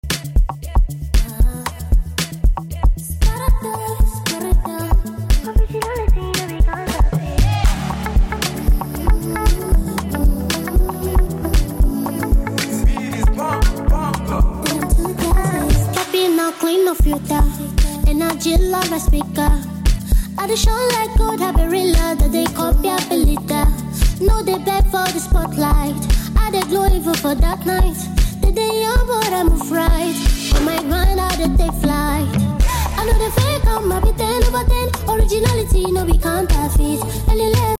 Afrobeat | Street | Stylish Vibes